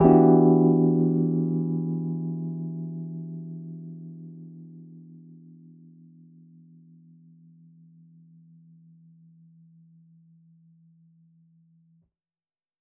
JK_ElPiano3_Chord-Em9.wav